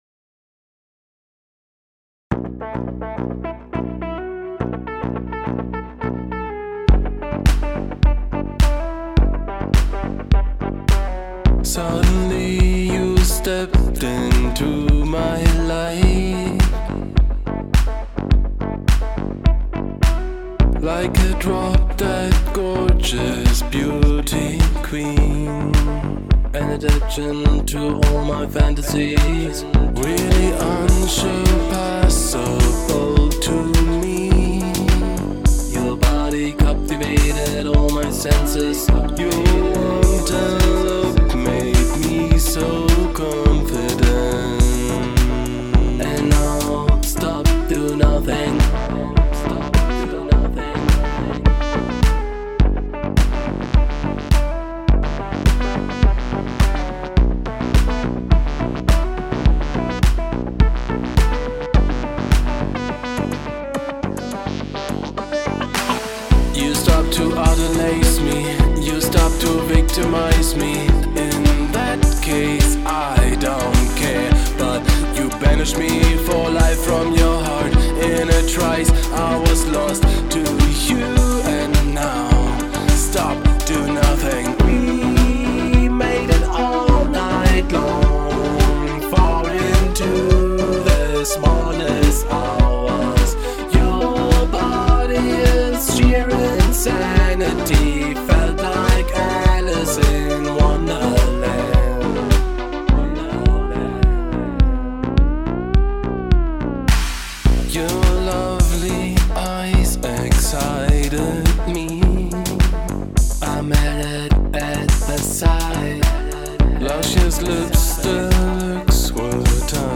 Synthpop Song - Feedback 2.
Bass überarbeitet Arrangement an hektischen Stellen aufgeräumt, entschlackt und vieles mehr Wie immer "OHNE" Masteringtools am Ausgang, also keine Kompression, kein Maximizer, etc. Lediglich ein leicht eingestellter Limiter ist am Start.